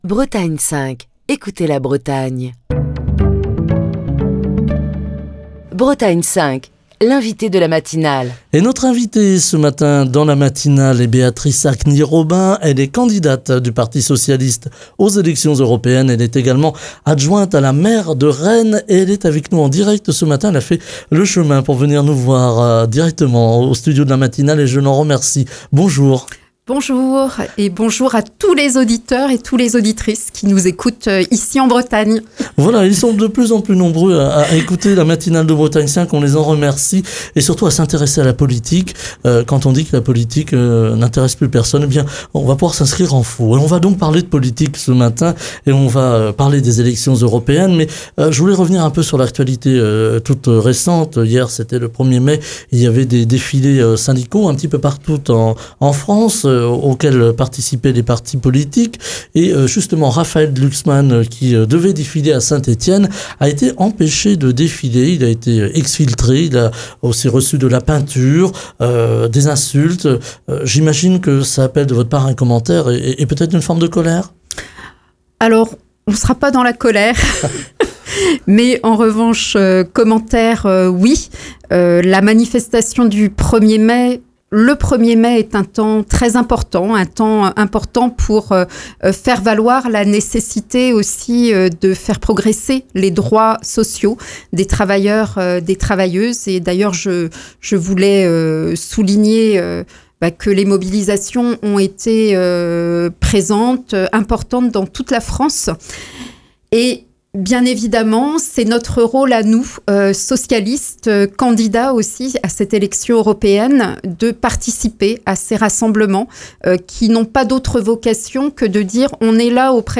Émission du 2 mai 2024. Béatrice Hakni-Robin, adjointe à la maire de Rennes, candidate du Parti socialiste aux élections européennes sur la liste "Réveiller l'Europe" conduite par Raphaël Glucksmann, est l'invitée politique de Bretagne 5 Matin, pour évoquer les grands thèmes de la campagne portés par le PS et Place publique.